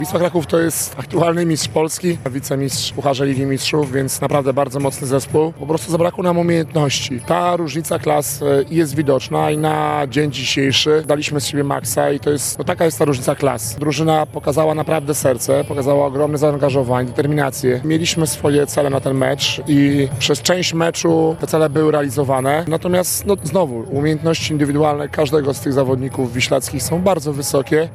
podsumował mecz w krótkiej wypowiedzi: